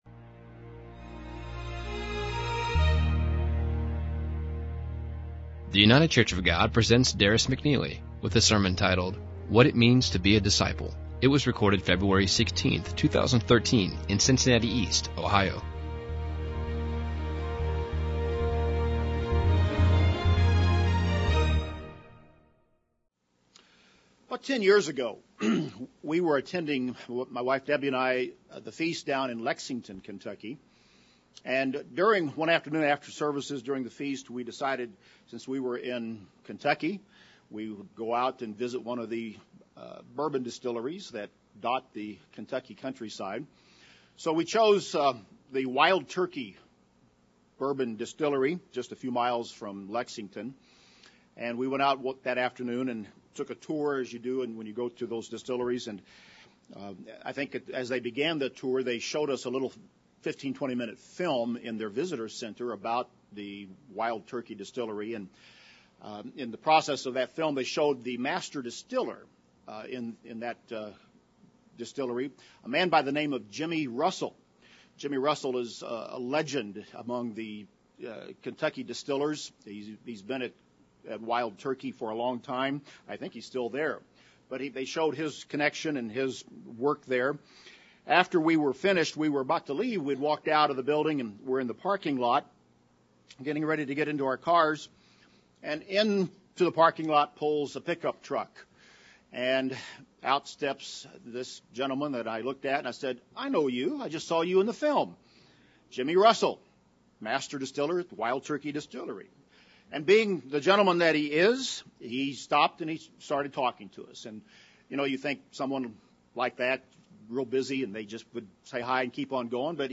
In this sermon, we will go through the scriptures to help us understand what it takes to be a disciple, how to make a disciple and to what it means to be a disciple.